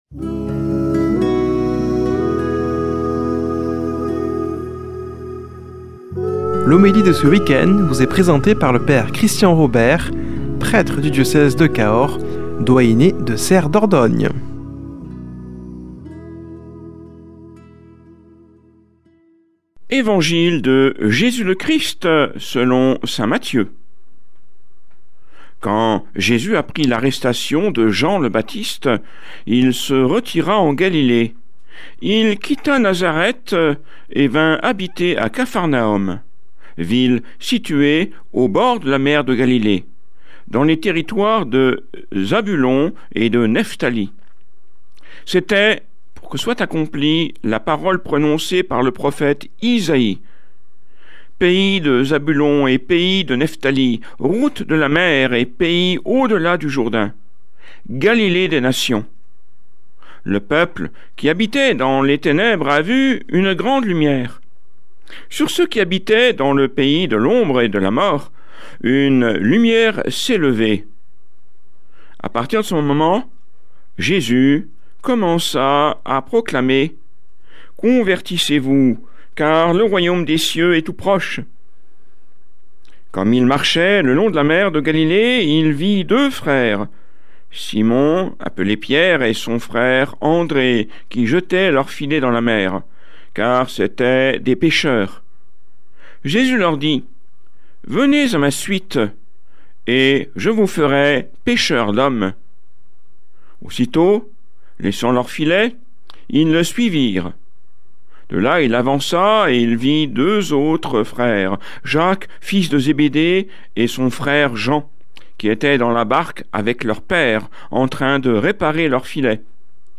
Homélie du 24 janv.